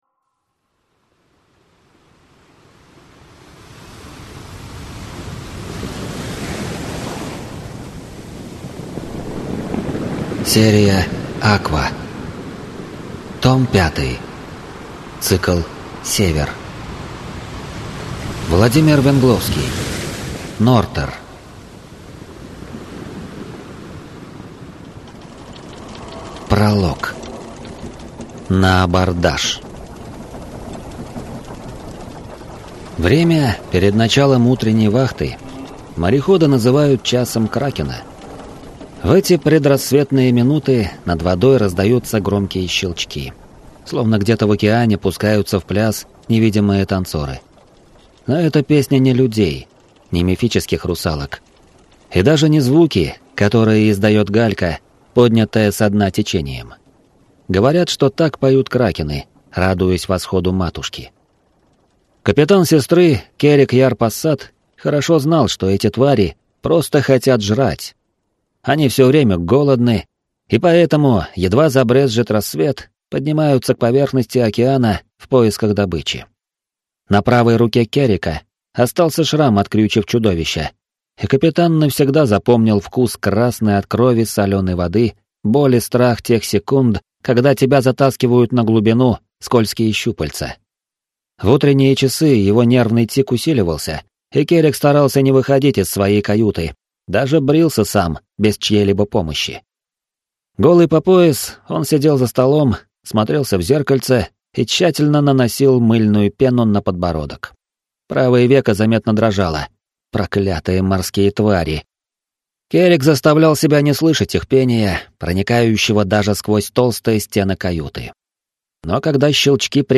Аудиокнига Аква 5. Нортер | Библиотека аудиокниг